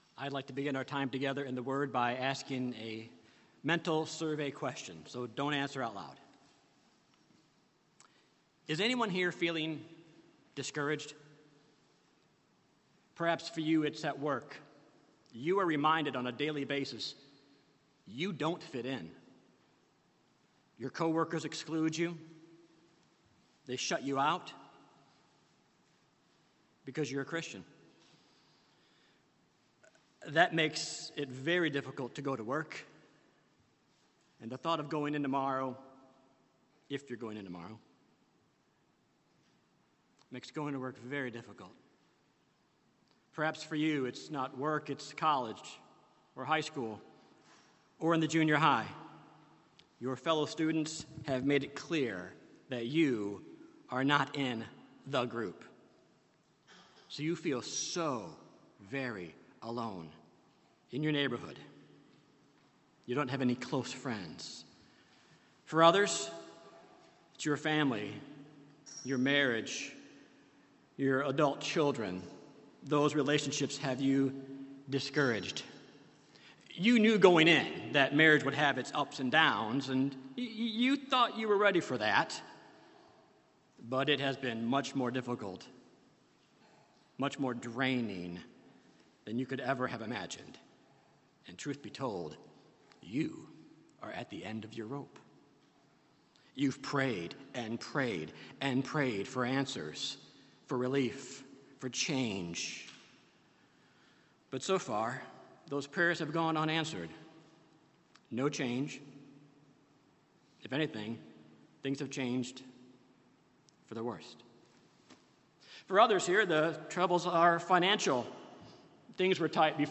Sermons on 1 Peter 1:1-2 — Audio Sermons — Brick Lane Community Church